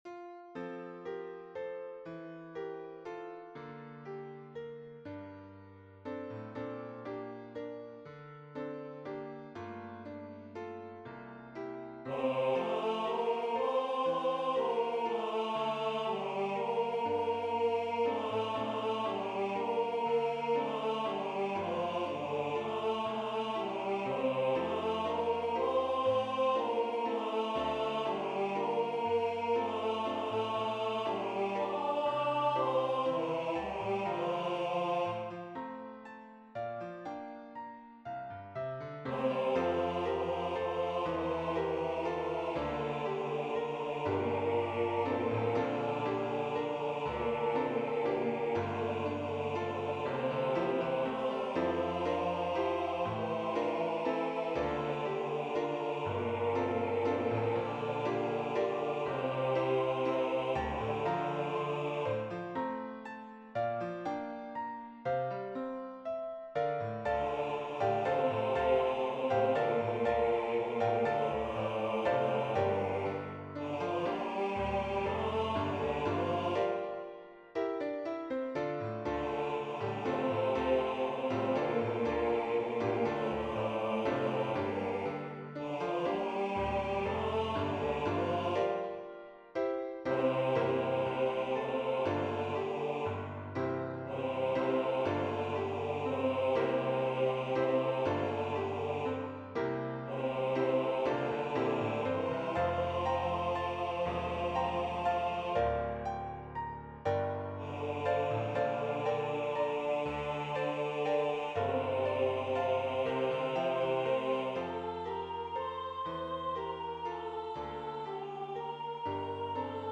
All voices Score (2026-02-02 05:41:07) Audio